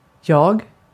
Uttal
Synonymer i ik Uttal : IPA: [jɑː(ɡ)] Okänd accent: IPA: /ˈjɑː/ IPA: /jɑːg/ Ordet hittades på dessa språk: svenska Översättning Pronomen 1. ben Artikel: ett .